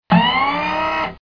Elevator up
Tags: Elevator Sounds Elevator Elevator Sound clips Elevator sound Sound effect